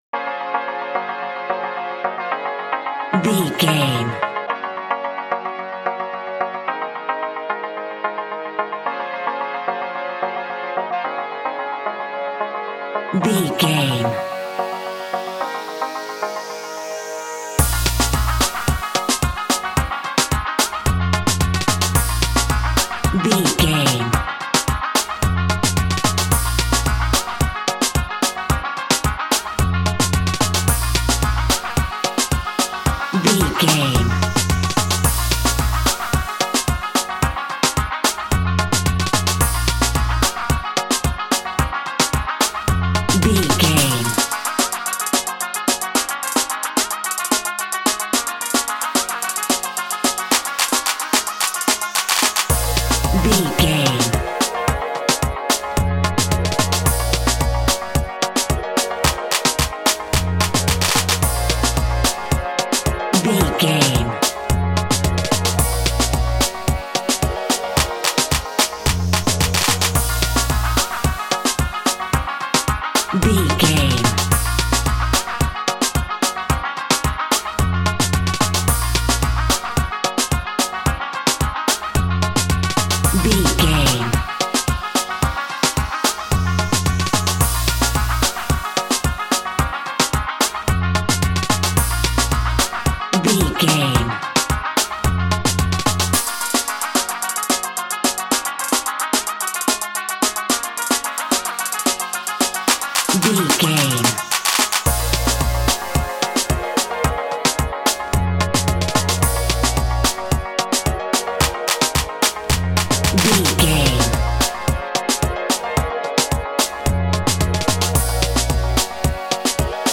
Classic reggae music with that skank bounce reggae feeling.
Ionian/Major
D
laid back
chilled
off beat
drums
skank guitar
hammond organ
percussion
horns